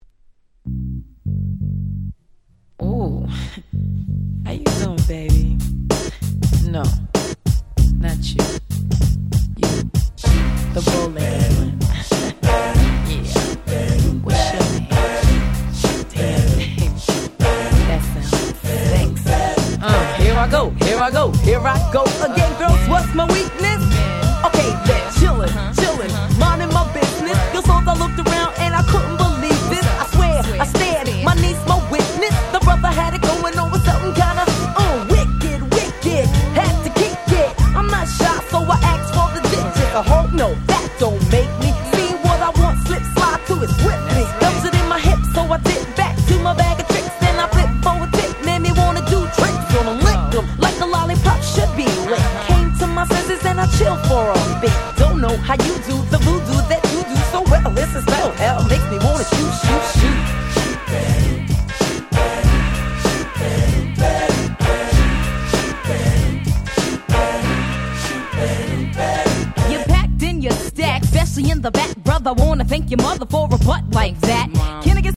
93' Super Hit Hip Hop !!
90's Boom Bap ブーンバップ